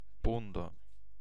Ääntäminen
Ääntäminen : IPA : /paʊnd/ US : IPA : [paʊnd]